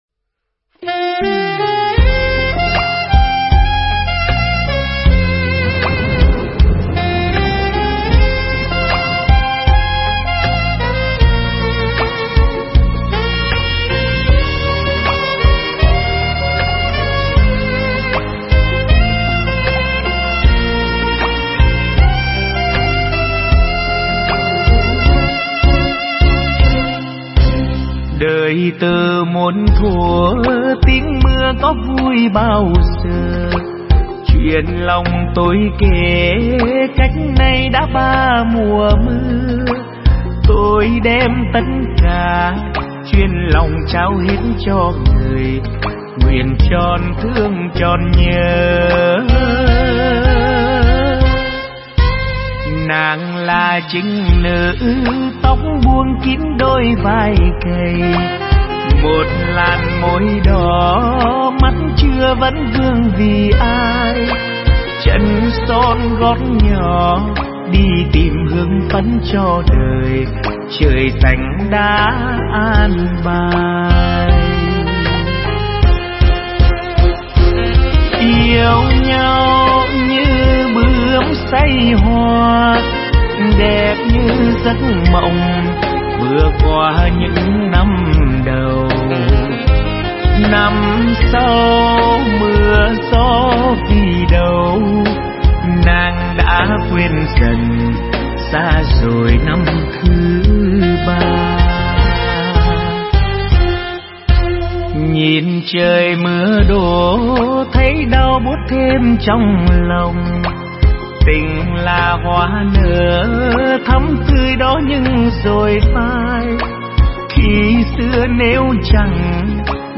Nhạc Bolero Trữ Tình